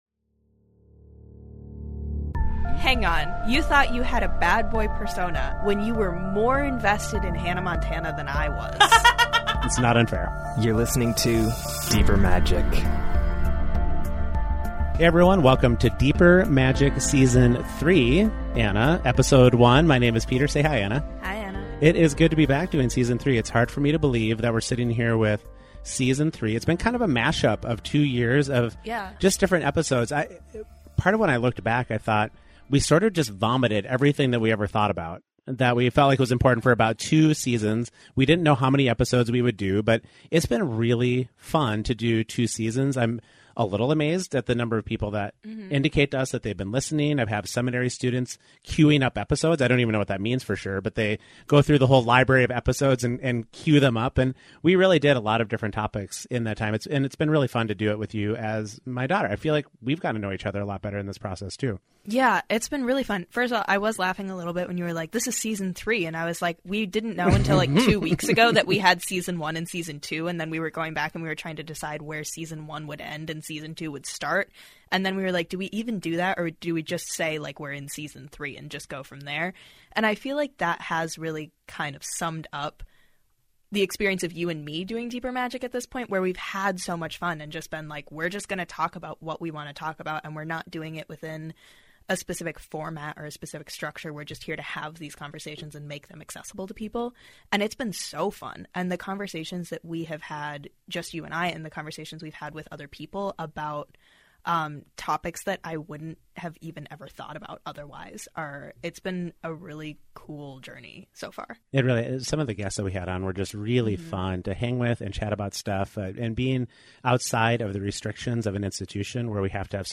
A new season of Deeper Magic has new voices!